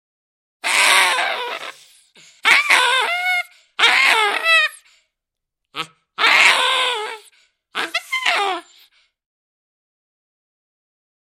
На этой странице собраны разнообразные звуки бабуина — от громких криков до ворчания и общения в стае.
Примат громко кричит